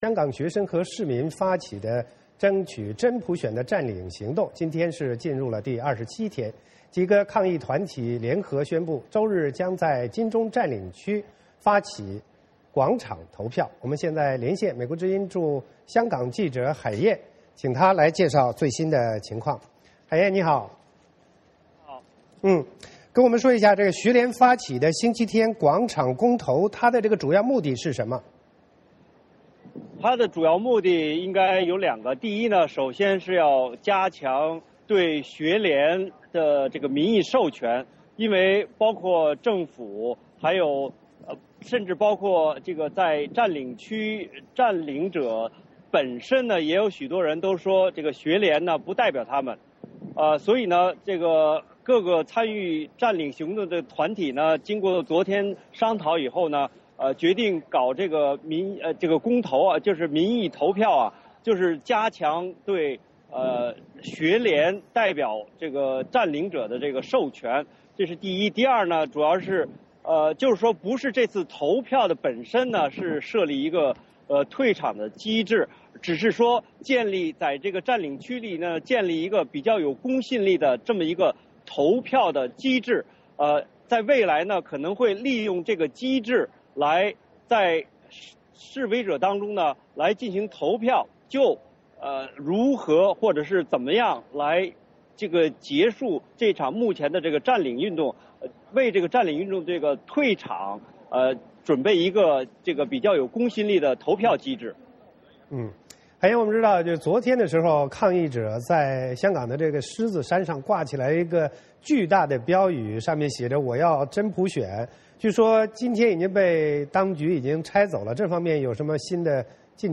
VOA连线：香港“占中”最新情况